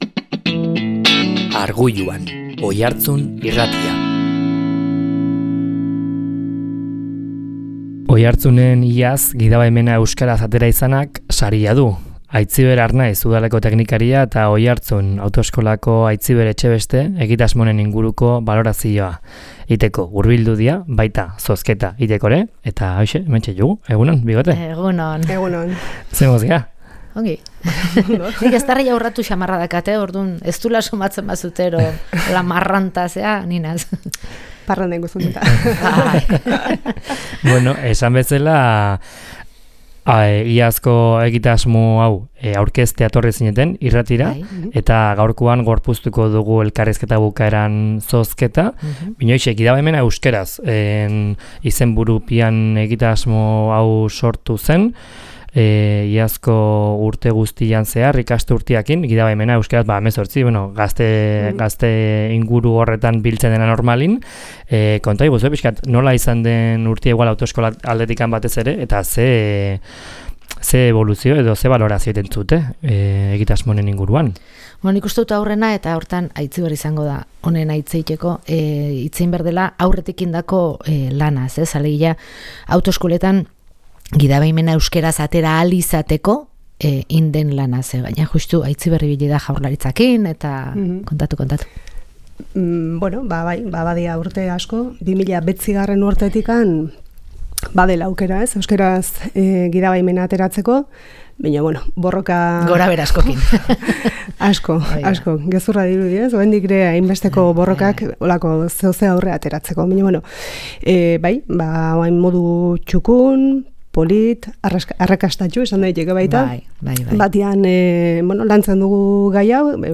egitasmo honen inguruko balorazioa egin dugu, baita zuzeneko zozketa ere